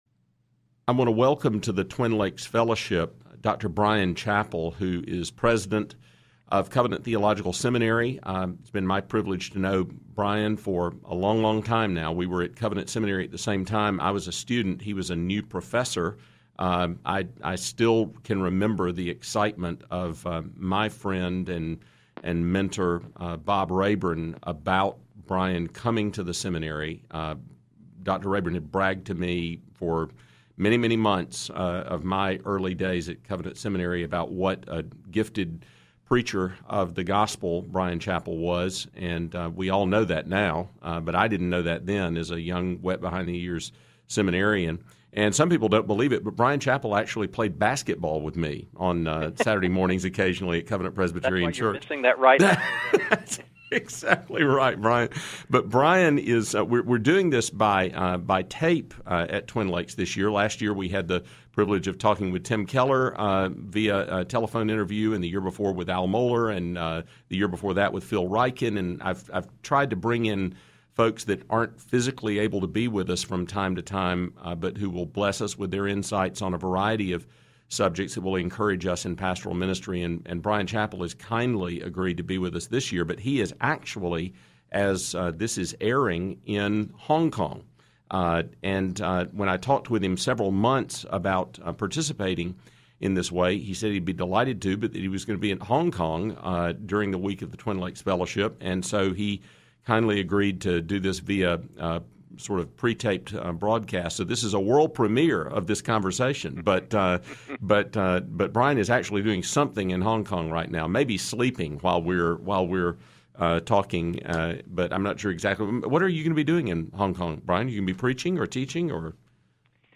Twin Lakes Fellowship Interview